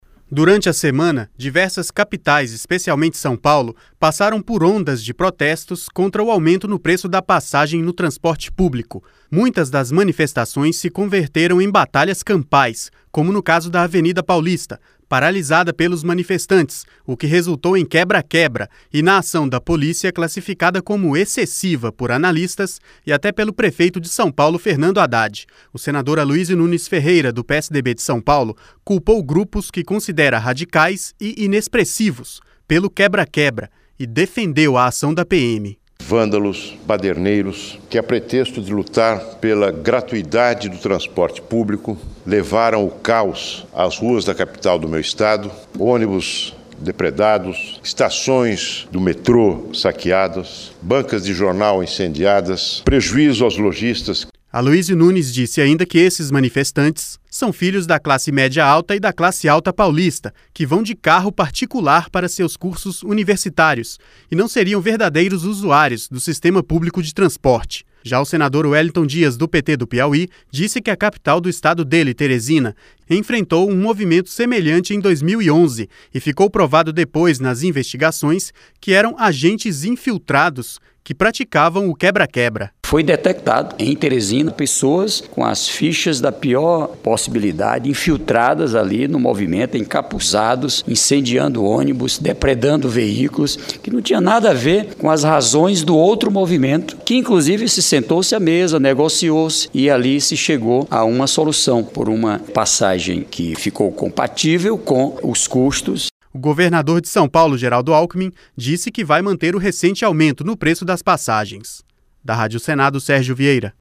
Senador Wellington Dias
Senador Aloysio Nunes Ferreira